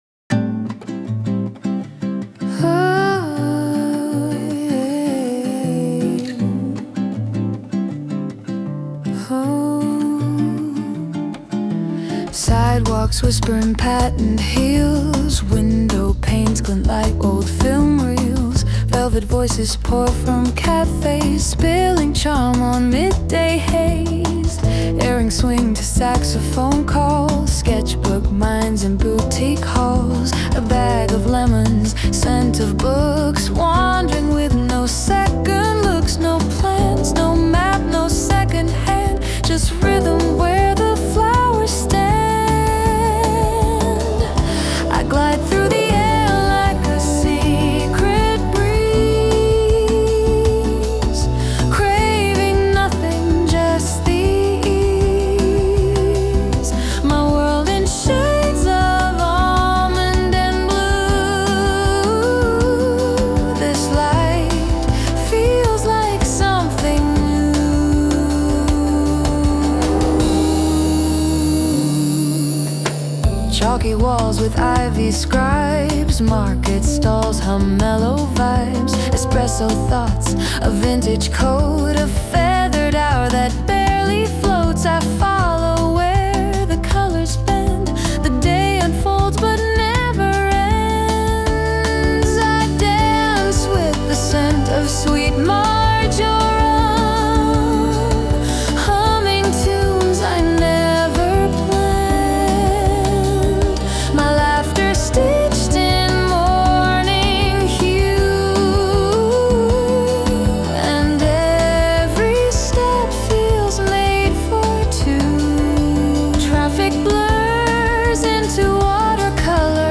洋楽女性ボーカル著作権フリーBGM ボーカル
女性ボーカル洋楽洋楽 女性ボーカル迎賓・歓談・お見送り作業BGMジャズ明るいおしゃれ優しい
著作権フリーオリジナルBGMです。
女性ボーカル（洋楽・英語）曲です。